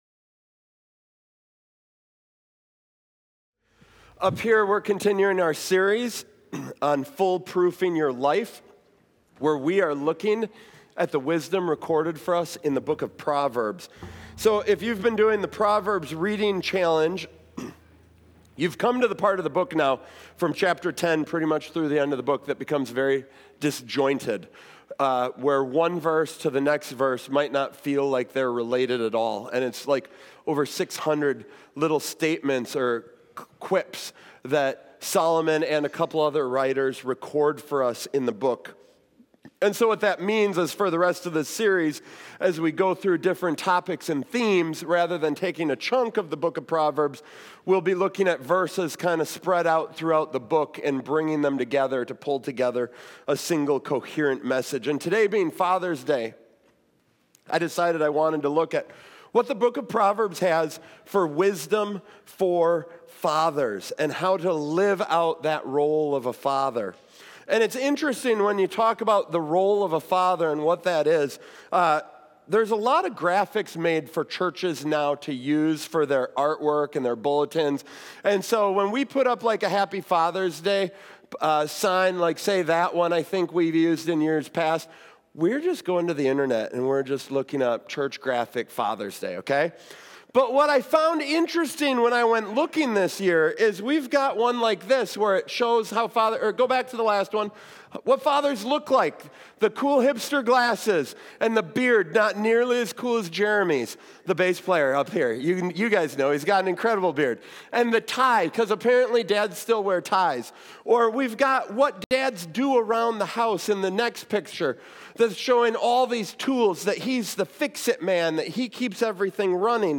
This Father’s Day message, drawn from the book of Proverbs, emphasizes that parents, not churches or schools, carry the primary responsibility for passing on both spiritual truth and practical life skills. This sermon highlights three essential parts of fatherhood.
Note: The video and audio for this message is shorter than usual due to some sound issues during the recording.